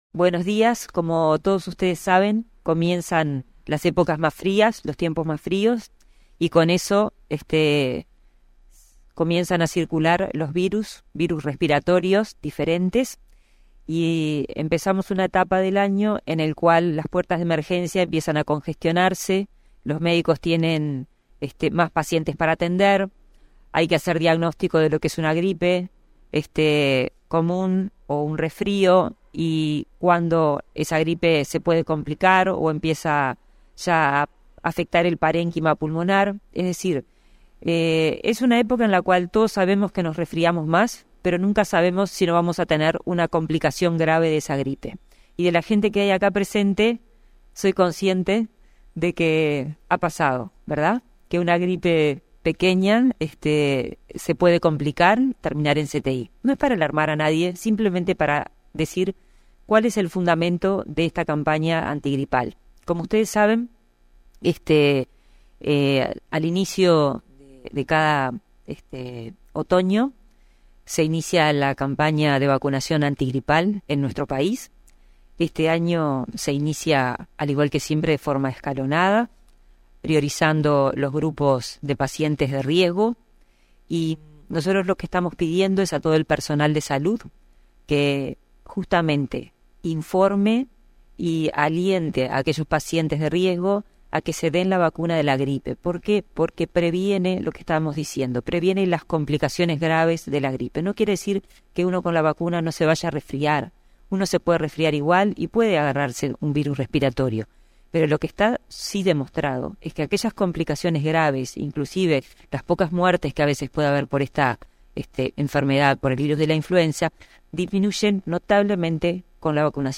Conferencia de prensa por el lanzamiento de la campaña de vacunación antigripal
Participaron em la actividad la titular de la cartera, Karina Rando; el subsecretario del organismo, José Luis Satdjian, y el director de la Unidad de inmunizaciones, Gabriel Peluffo.